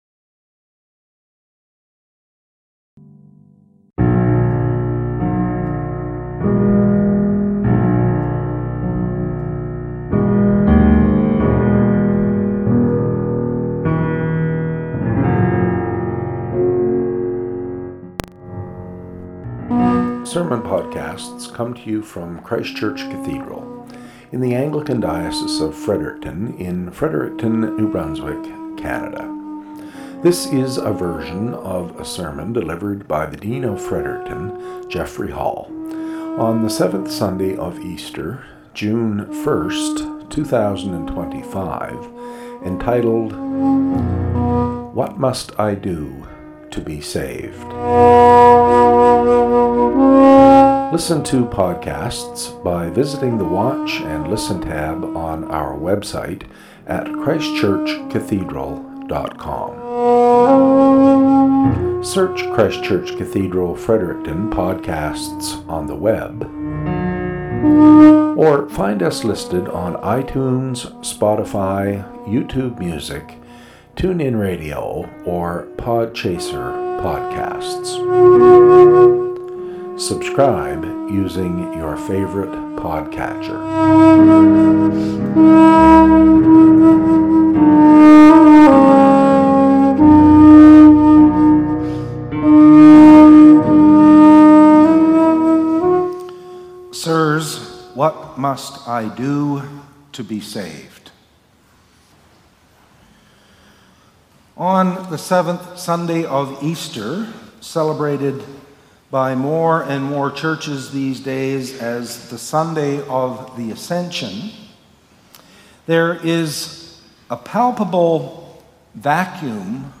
Podcast from Christ Church Cathedral Fredericton